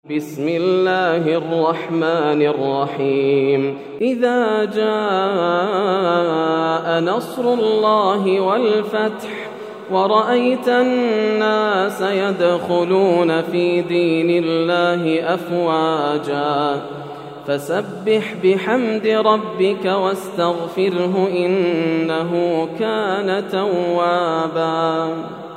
سورة النصر > السور المكتملة > رمضان 1431هـ > التراويح - تلاوات ياسر الدوسري